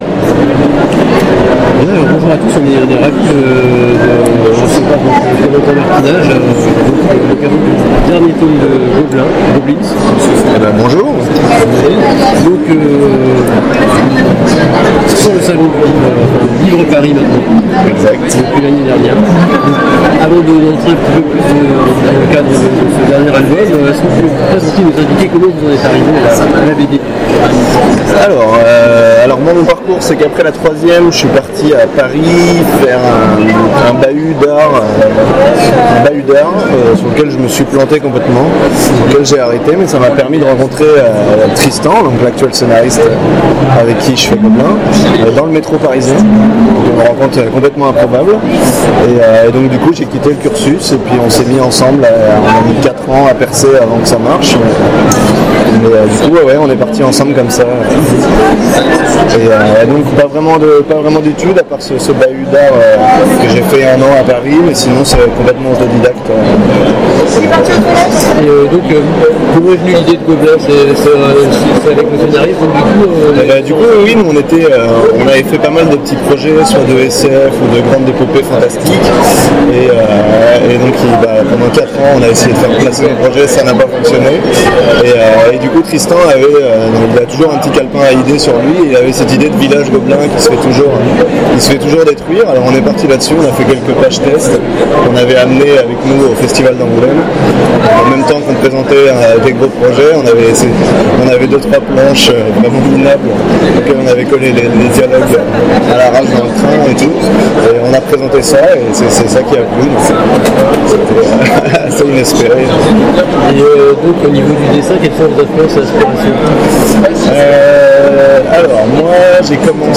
Interview 2017